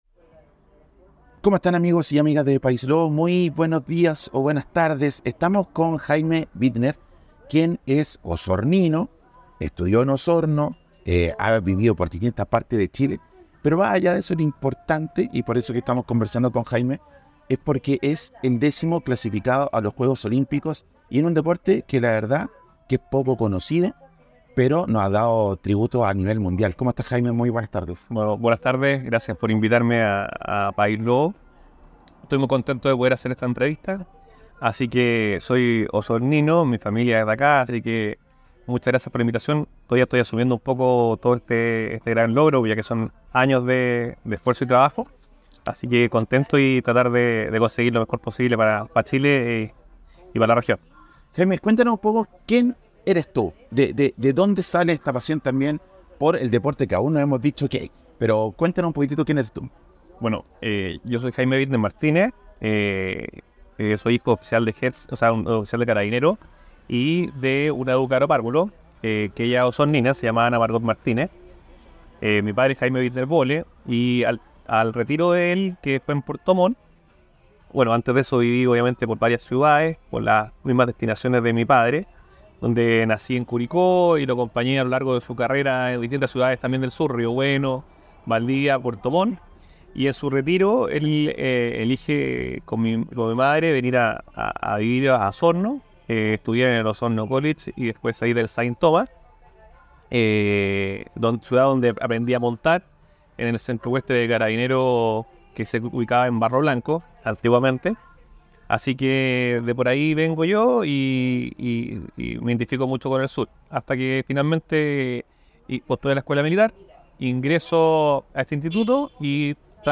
En una inspiradora conversación con PaísLobo Prensa